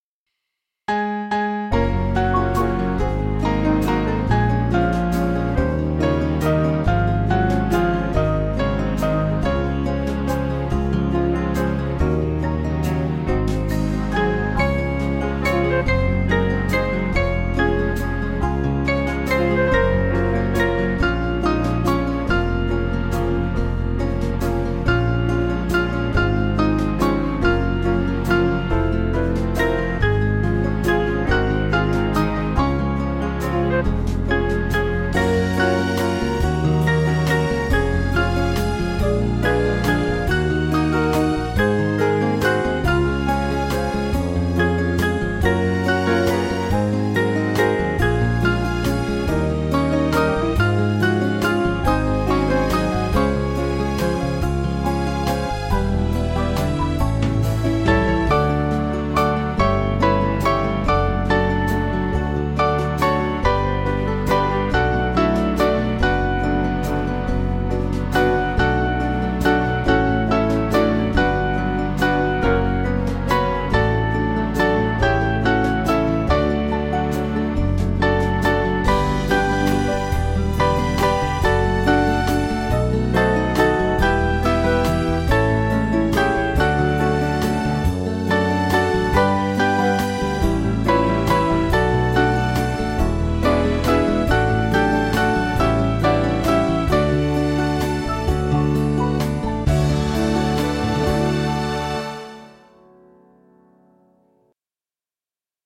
Small Band
(CM)   2/Db-D 541kb